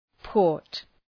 Προφορά
{pɔ:rt}